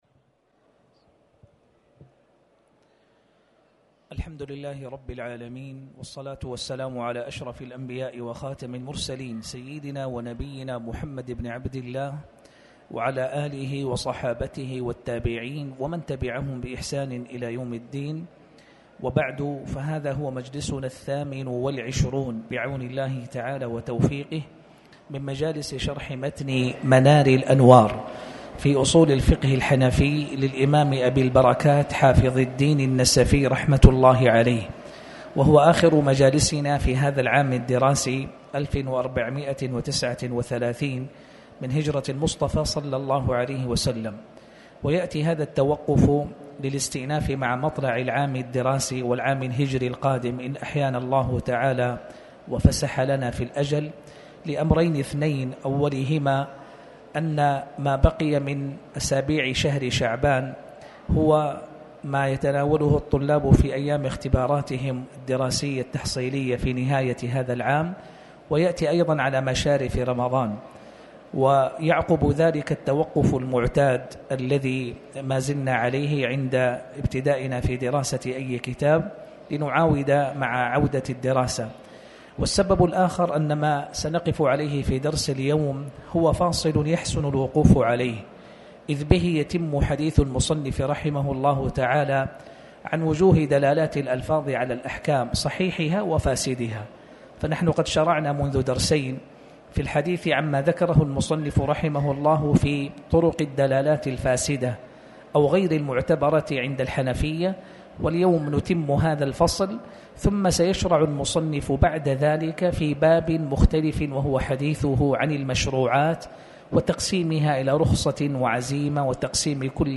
تاريخ النشر ٨ شعبان ١٤٣٩ هـ المكان: المسجد الحرام الشيخ